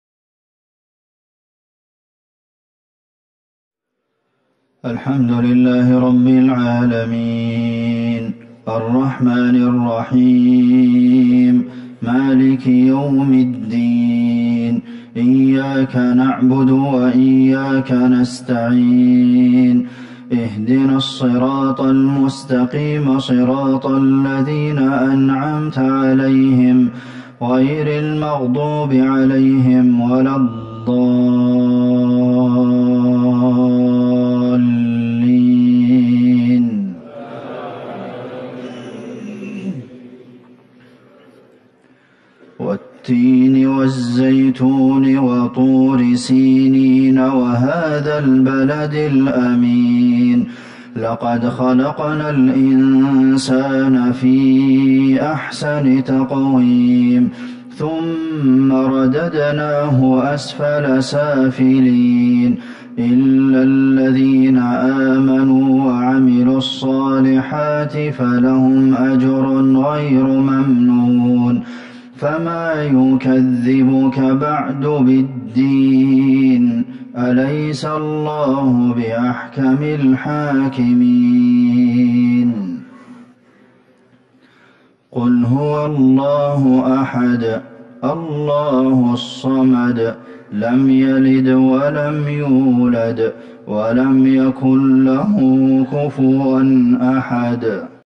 صلاة المغرب ١٨ جمادي الاولى ١٤٤١هـ سورة التين والاخلاص Maghrib prayer 6-1-2020 ftom Surat Al-Tin and Al-Ikhlas > 1441 🕌 > الفروض - تلاوات الحرمين